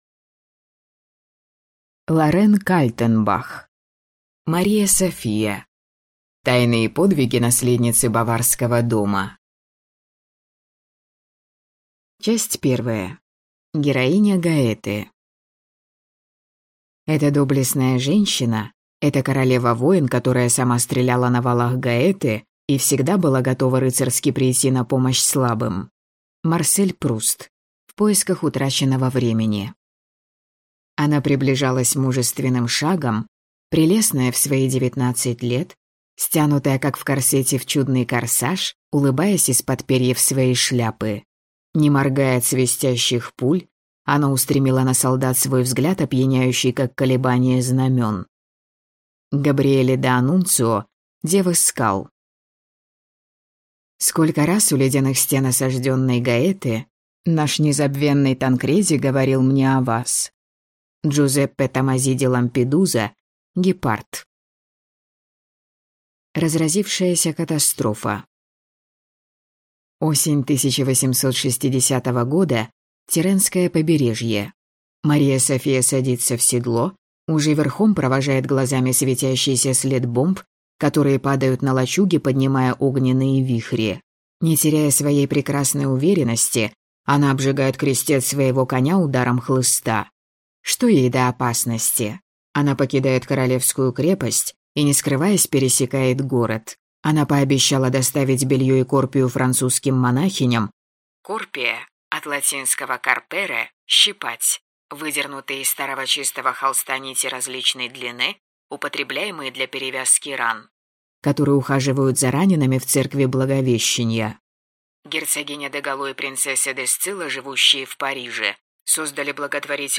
Аудиокнига Мария София: тайны и подвиги наследницы Баварского дома | Библиотека аудиокниг